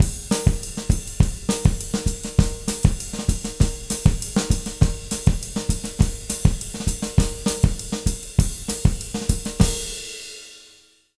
100SONGO04-R.wav